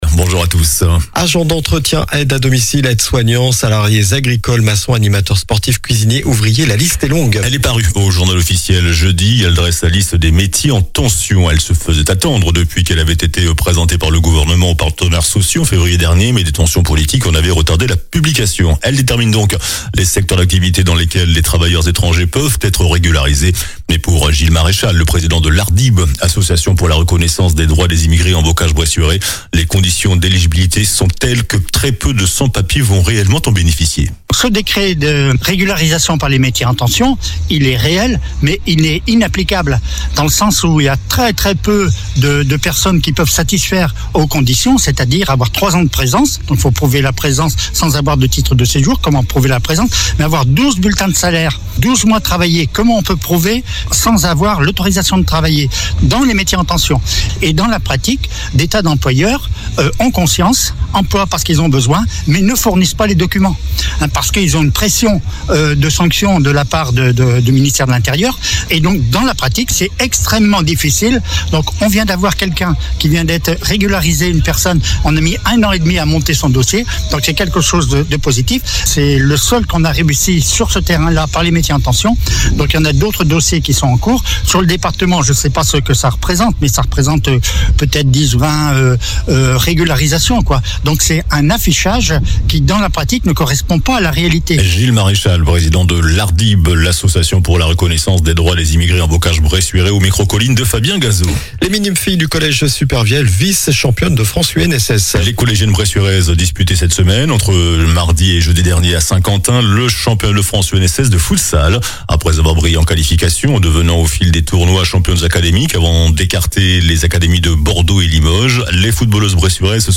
JOURNAL DU SAMEDI 24 MAI